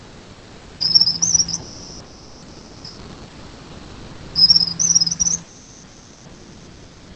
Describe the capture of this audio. Country: Argentina Location or protected area: Villa Rosa Condition: Wild